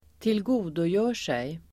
Uttal: [²tilg'o:dojö:r_sej]